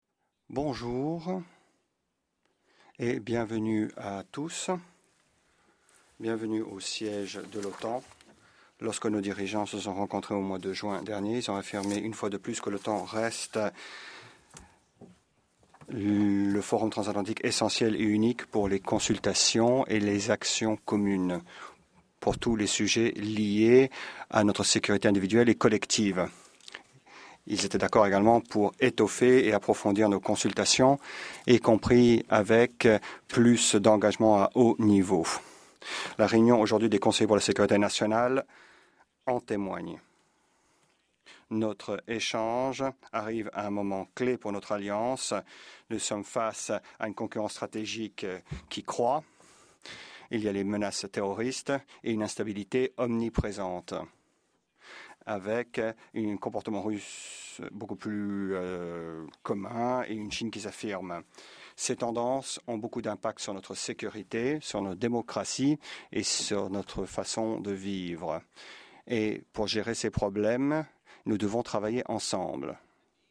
Opening remarks
by the NATO Secretary General Jens Stoltenberg at the start of the North Atlantic Council Meeting with the National Security Advisers